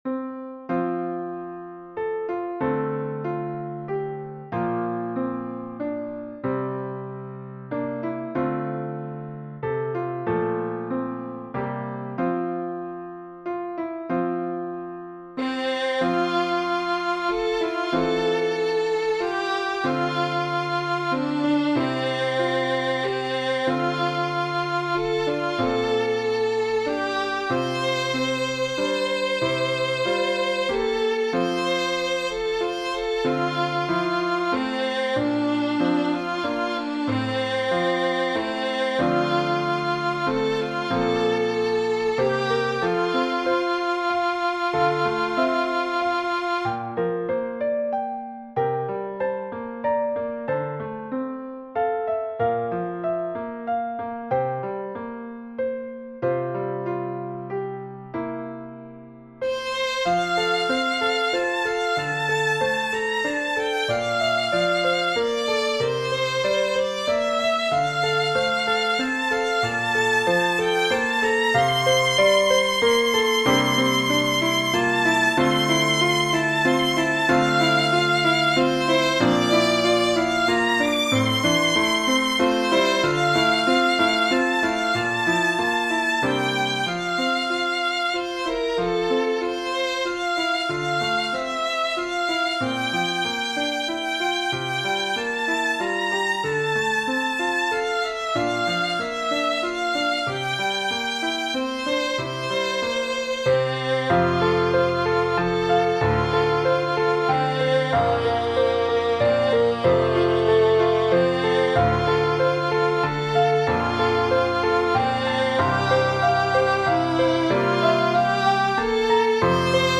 It features both instruments with melody parts.